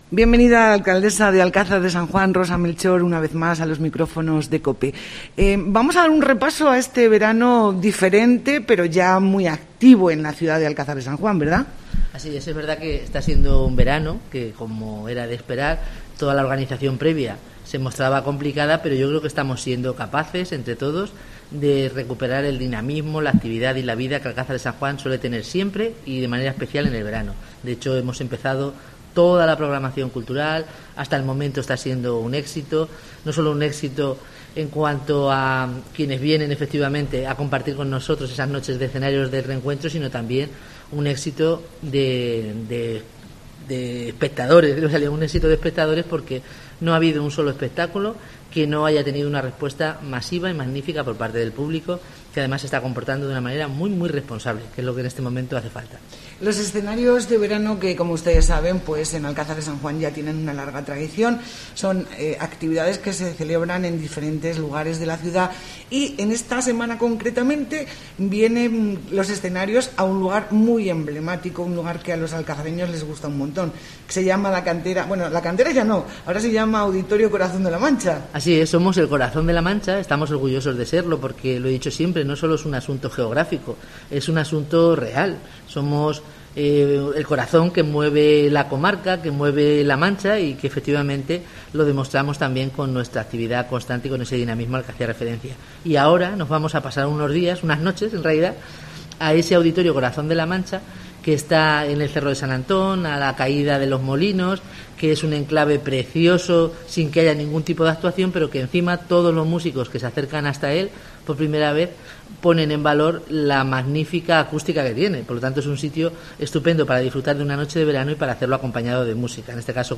Entrevista a Rosa Melchor, alcaldesa de Alcázar de San Juan